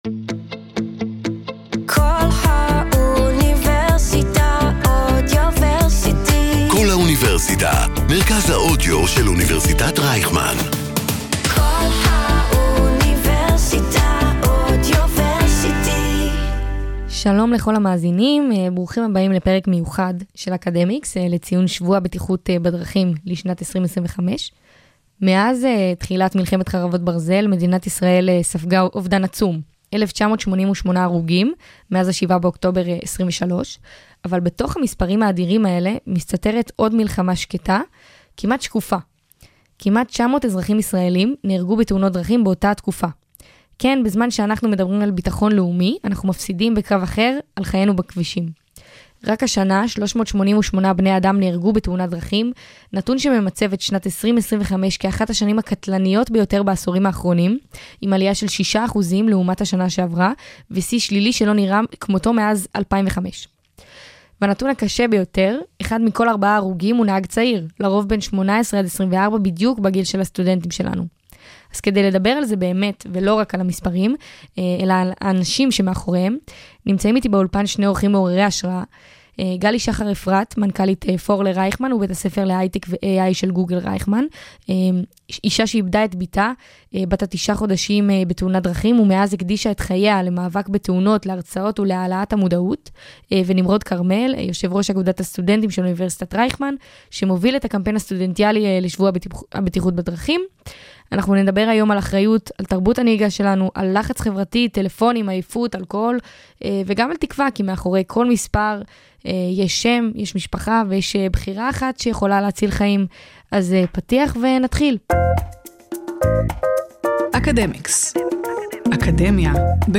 הגיעו היום לאולפן שני אורחים מעוררי השראה